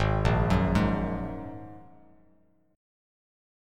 Ab7sus2 chord